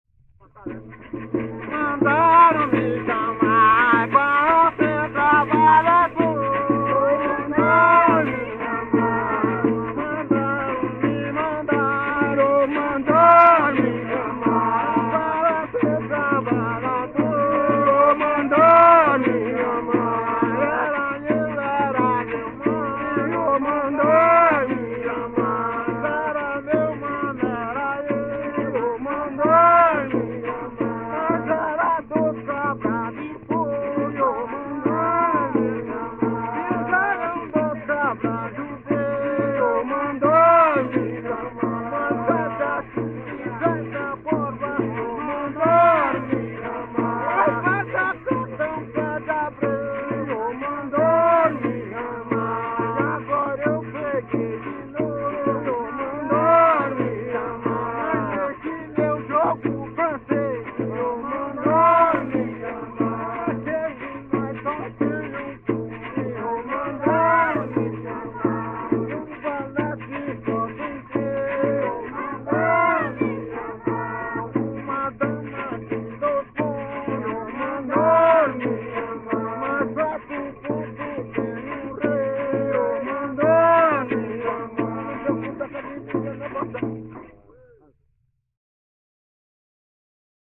Coco embolada